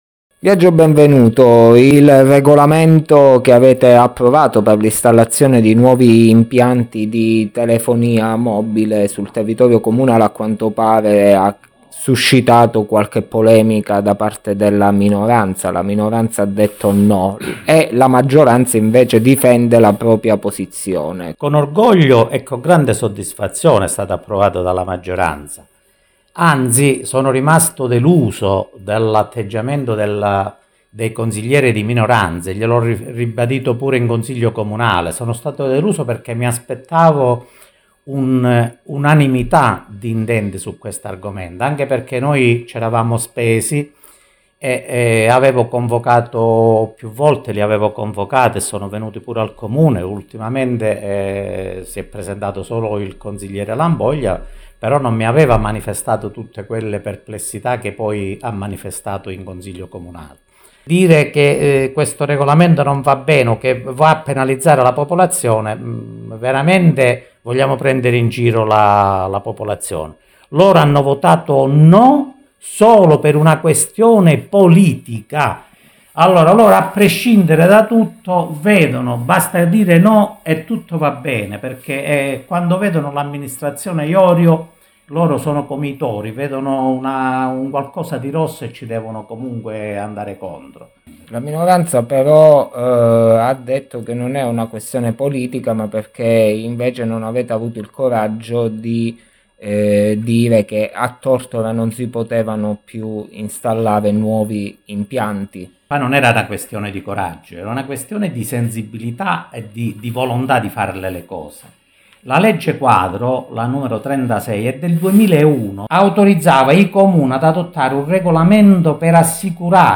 Non si placa il dibattito sull’approvazione del regolamento per l’installazione di impianti di telefonia sul territorio comunale di Tortora. A rispondere agli interventi della minoranza, il vicesindaco della Giunta Iorio, Biagio Benvenuto.
INTERVISTA_BENVENUTO.mp3